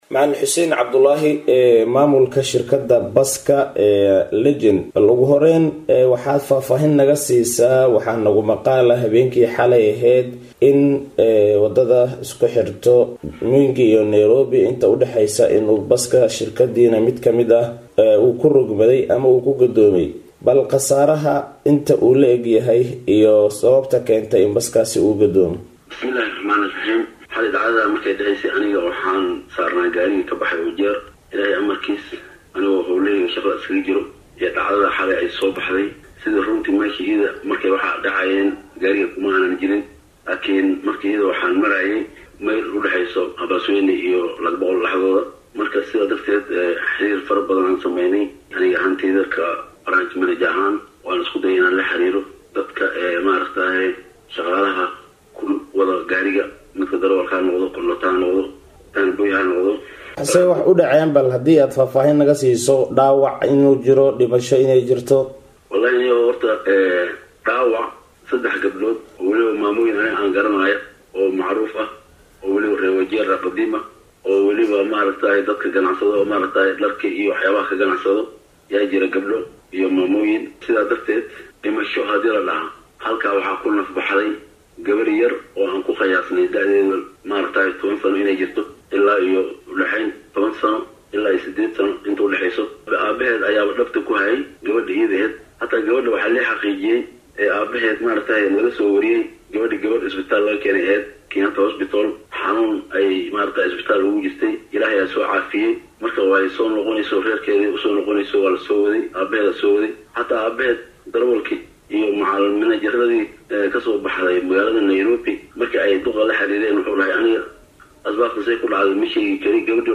DHAGEYSO:Faahfaahino ka soo baxaya baska Legend oo xalay shil galay